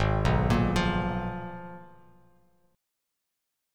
G#m6add9 chord